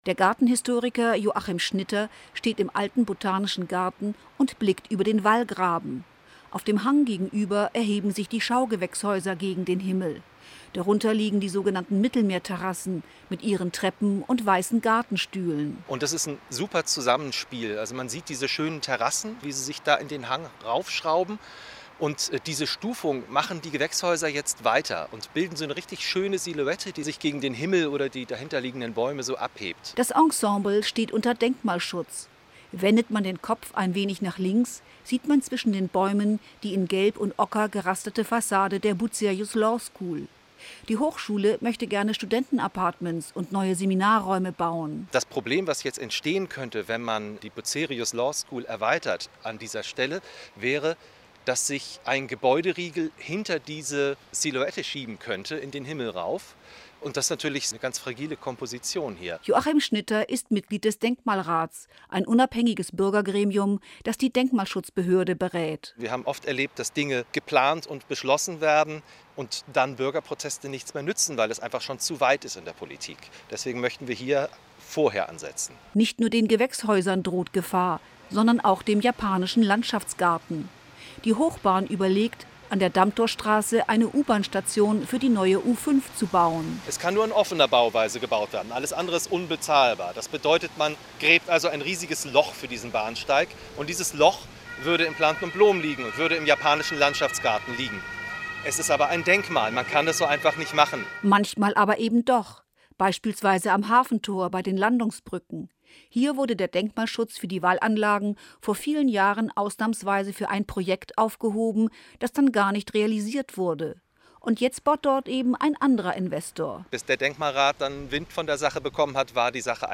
Radiobericht: Denkmal Wallringpark